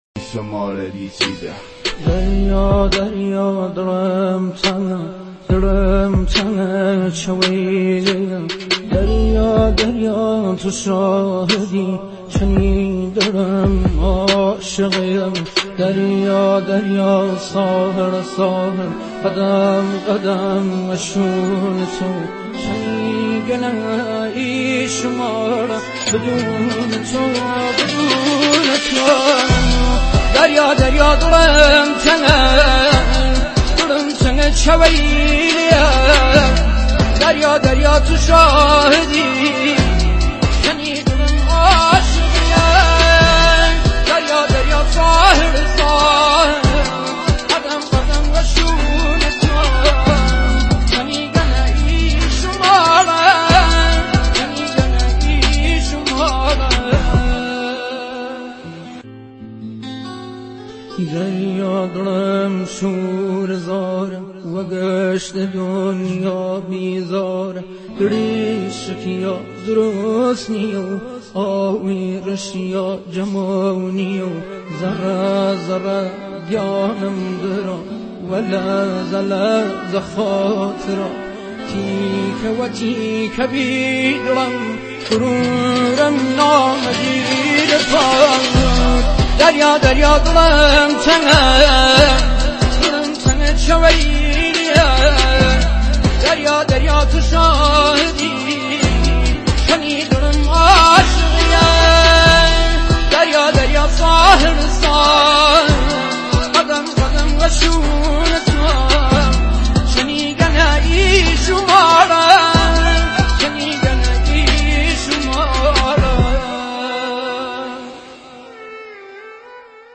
Download Old Remix BY